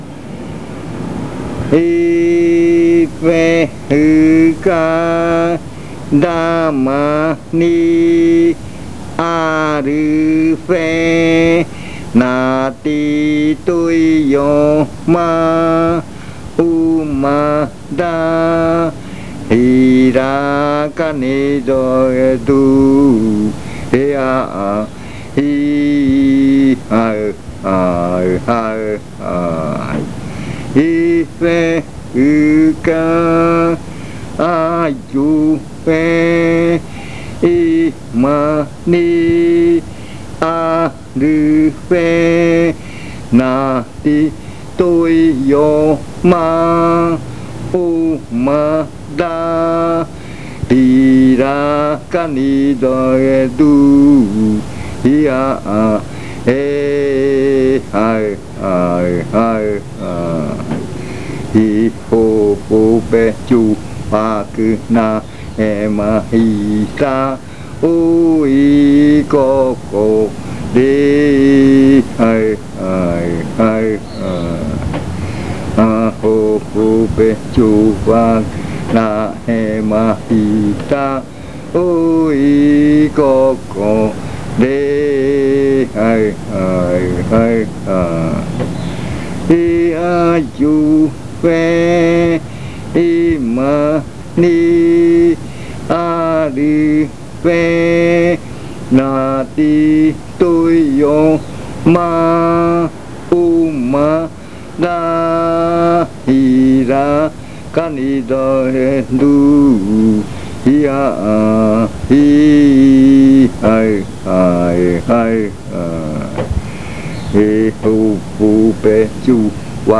Leticia, Amazonas
Este canto hace parte de la colección de cantos del ritual Yuakɨ Murui-Muina (ritual de frutas) del pueblo Murui
This chant is part of the collection of chants from the Yuakɨ Murui-Muina (fruit ritual) of the Murui people
singer